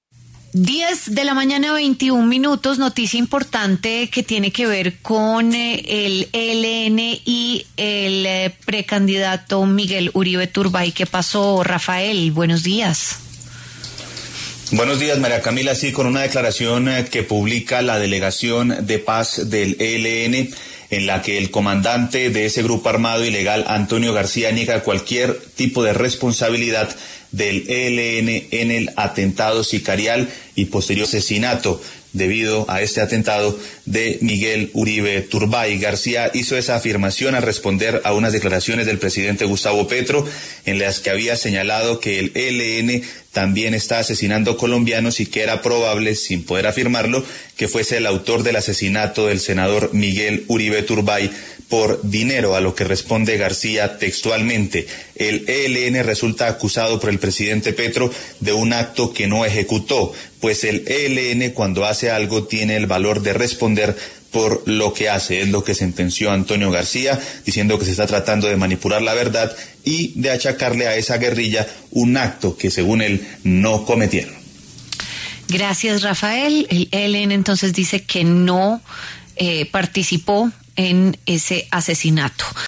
El comandante de la guerrilla del ELN, Antonio García, por medio de una declaración negó cualquier responsabilidad de ese grupo armado ilegal en el ataque sicarial contra el precandidato presidencial Miguel Uribe Turbay.